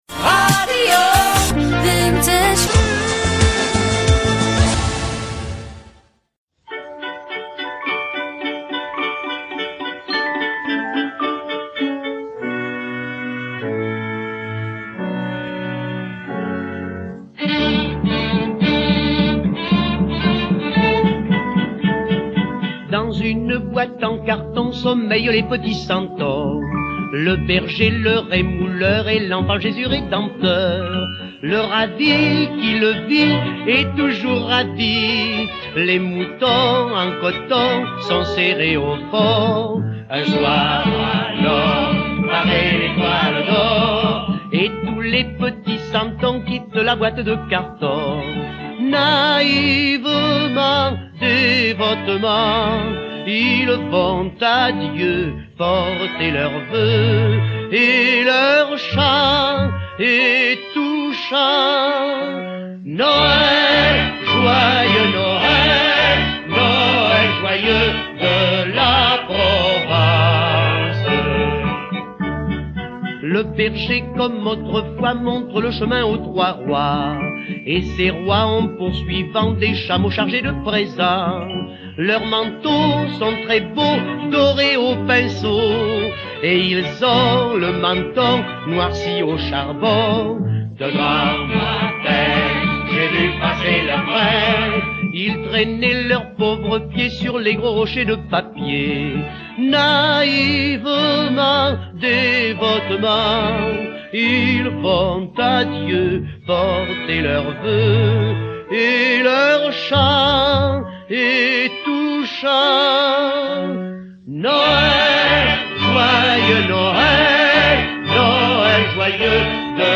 Redécouvrez les plus belles chansons de Noël anciennes